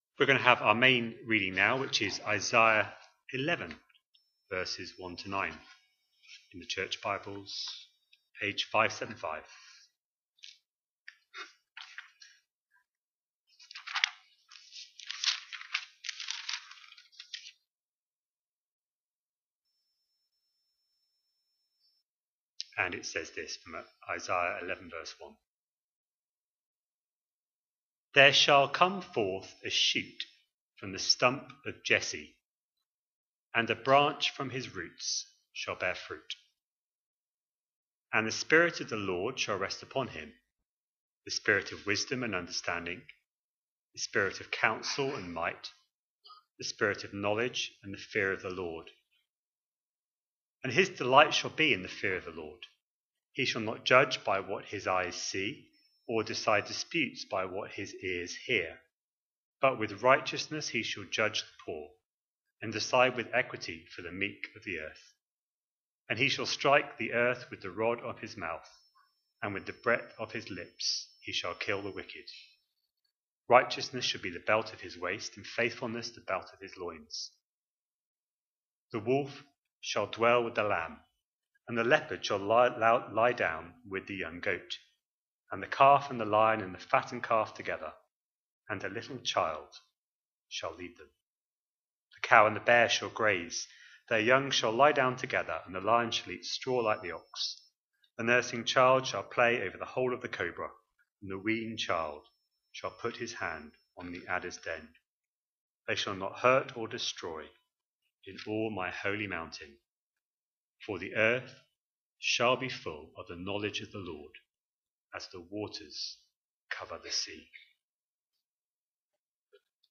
A sermon preached on 21st December, 2025, as part of our Christmas 2025 series.